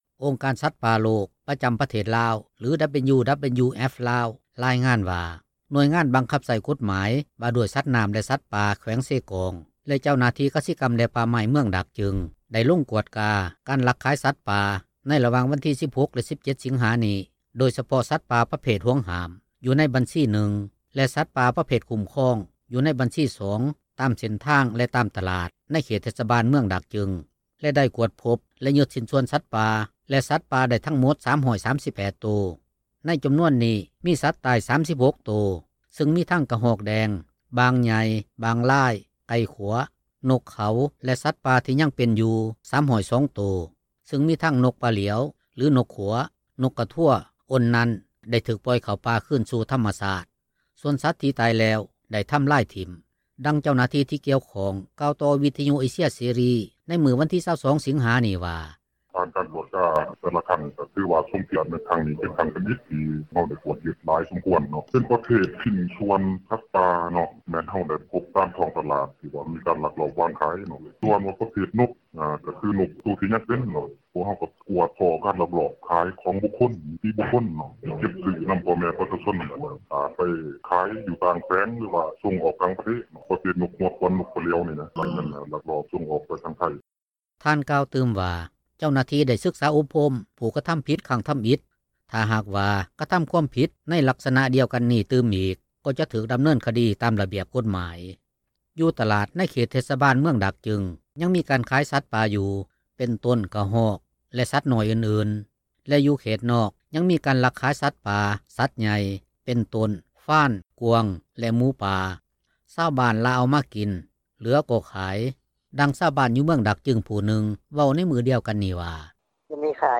ດັ່ງຊາວບ້ານ ຢູ່ເມືອງດາກຈຶງ ຜູ້ນຶ່ງເວົ້າໃນມື້ດຽວກັນນີ້ວ່າ:
ດັ່ງທີ່ເຈົ້າໜ້າທີ່ ທີ່ກ່ຽວຂ້ອງເວົ້າວ່າ: